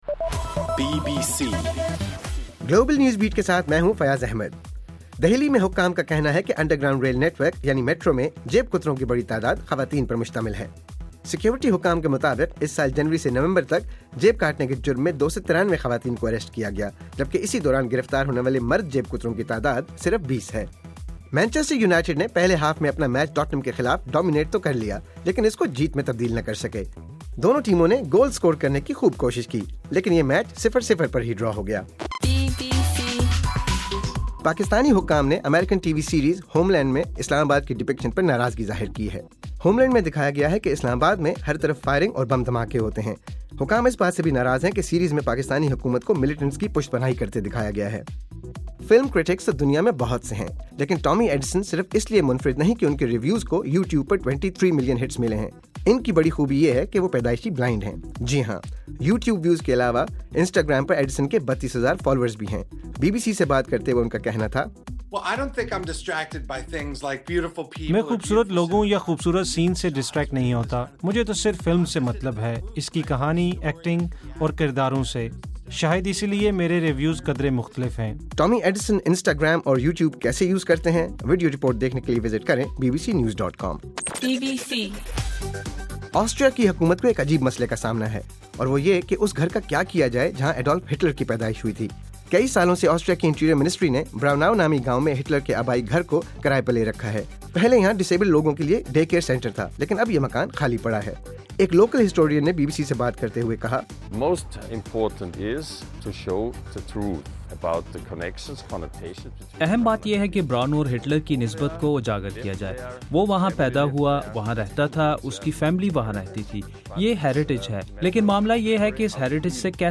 دسمبر 28: رات 10 بجے کا گلوبل نیوز بیٹ بُلیٹن